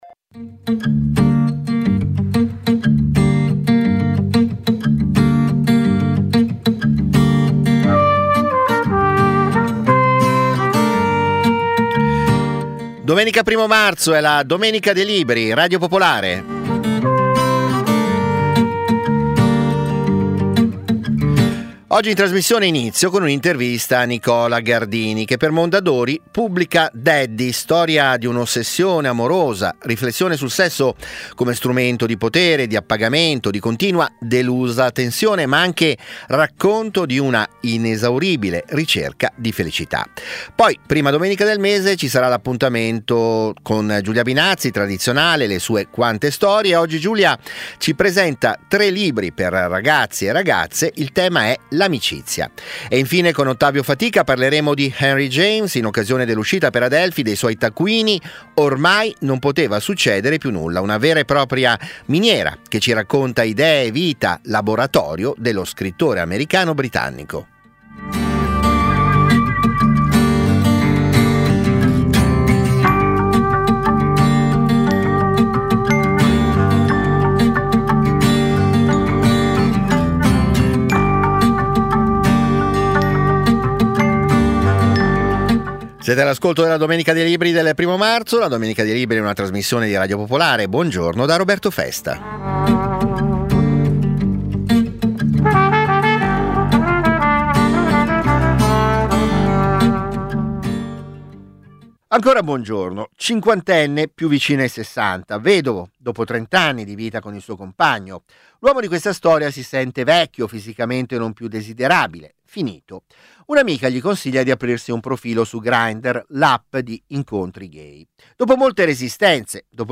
- Intervista a Nicola Gardini, autore di "Daddy" per Mondadori.